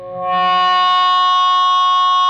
PRS FBACK 4.wav